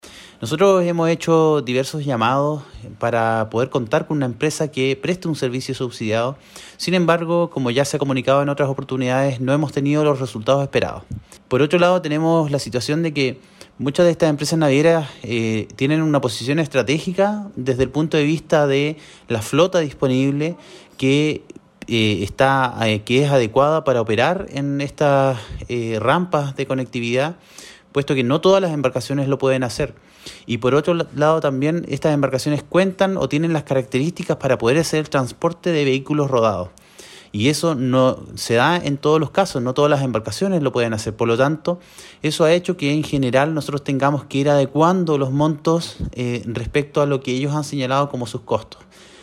Resumió el seremi de Transportes Los Lagos que el objetivo de estas licitaciones es resguardar un buen servicio de transbordo en ambos cruces marítimos y así poder dar conectividad a las comunidades insulares de Chiloé.